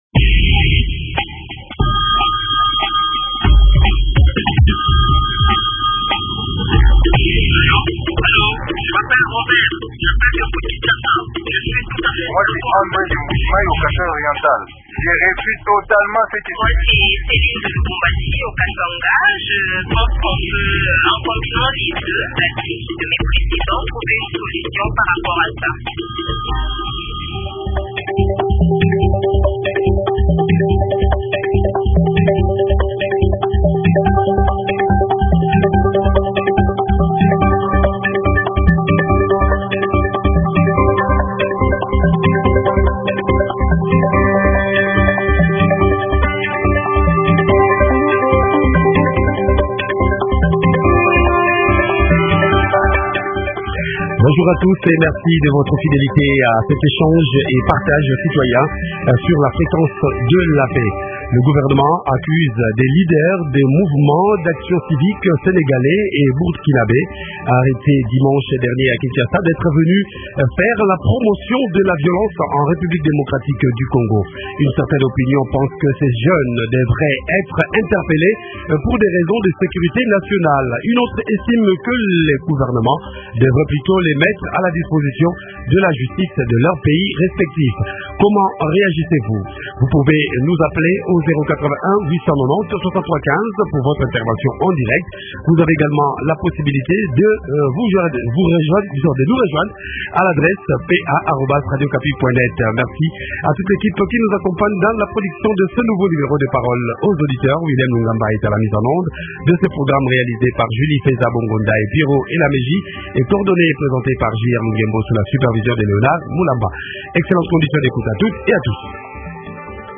Analyste politique Fichier audio